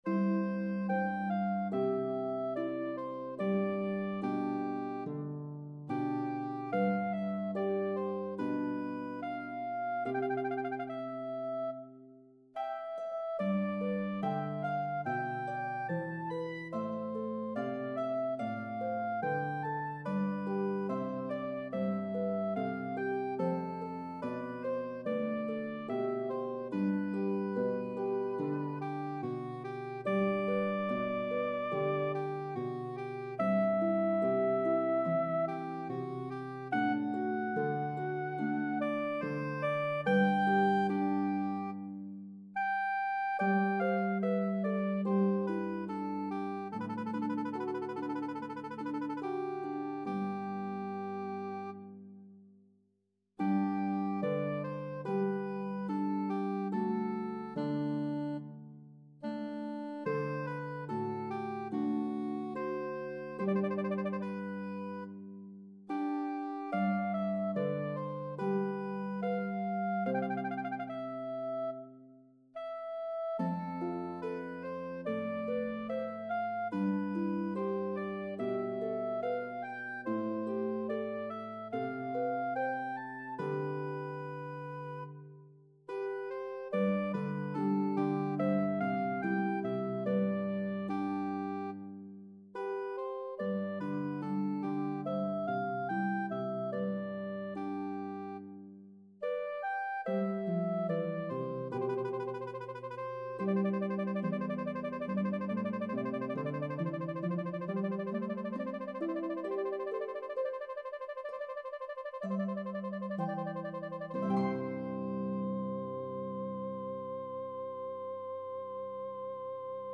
The melody utilizes a range of a 12th.